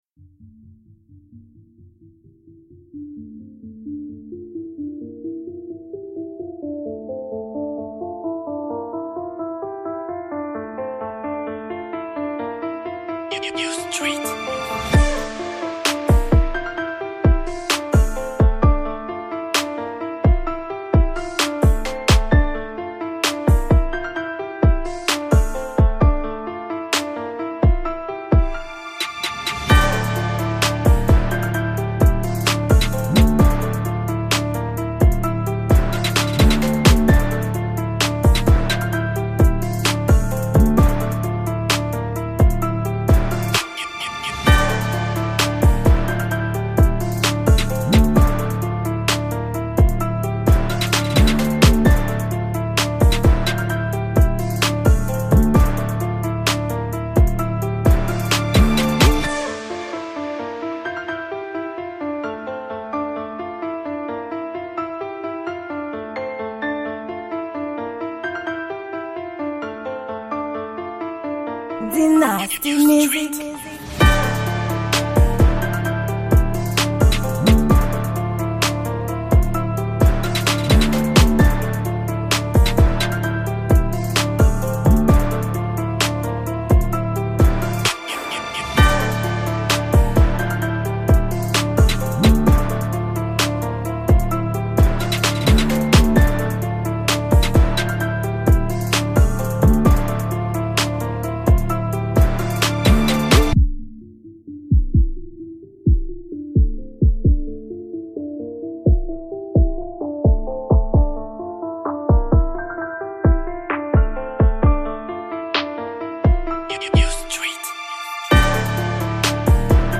Genre: Beat